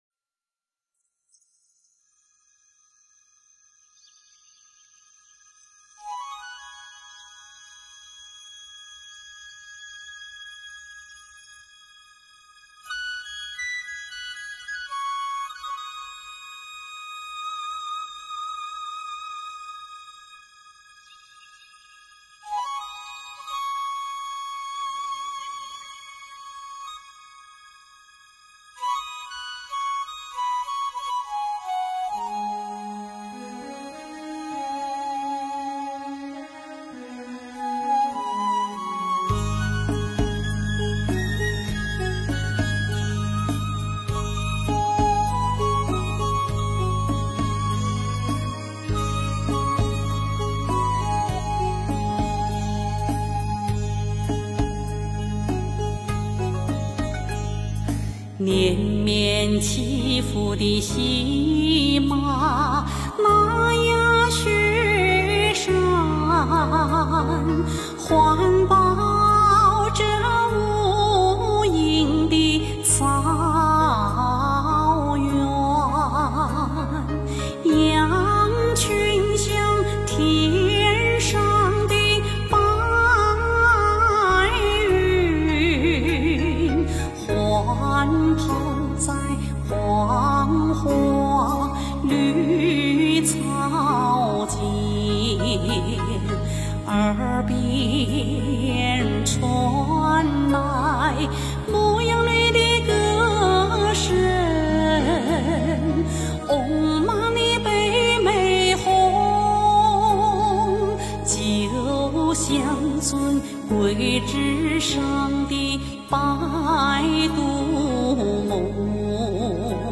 佛音 诵经 佛教音乐 返回列表 上一篇： 指月 下一篇： 原来退步是向前 相关文章 月满西楼 月满西楼--古筝...